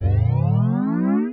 Acceleration sounds
ascend-chime-cartoon.mp3